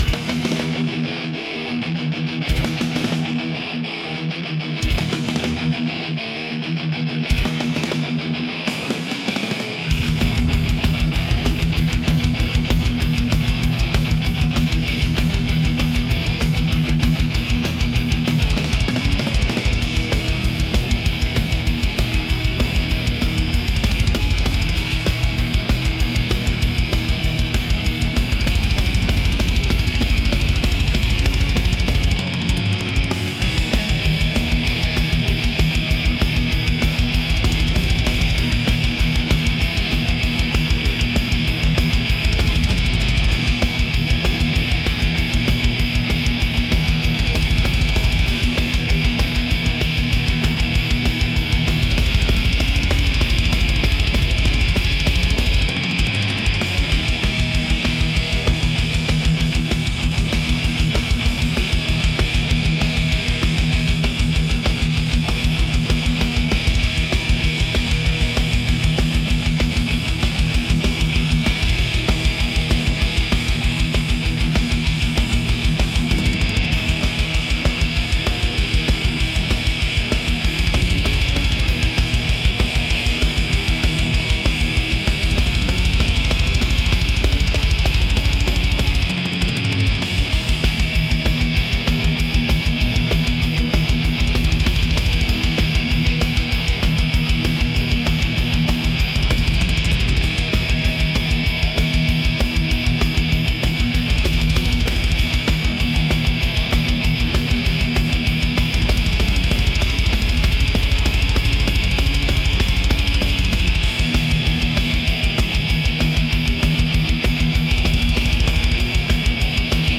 intense | aggressive | metal